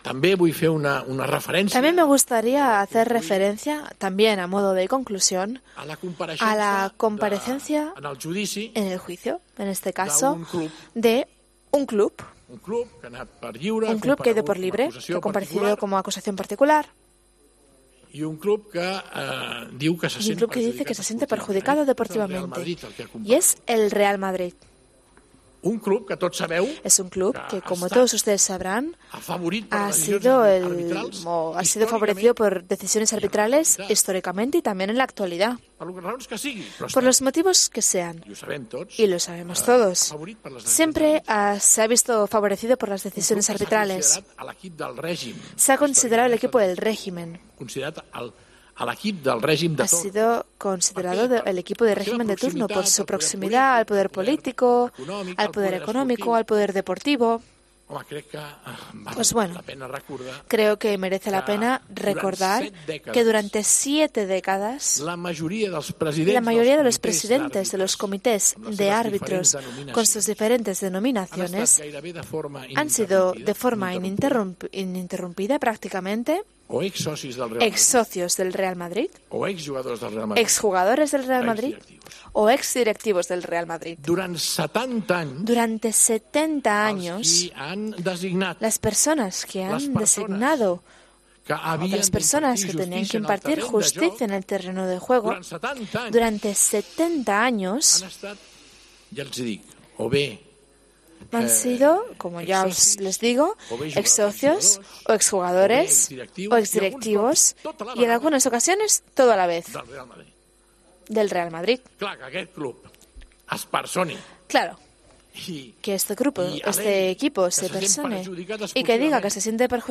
El presidente del Barcelona lanzó un dardo al máximo equipo rival durante su rueda de prensa de explicación por el caso Negreira.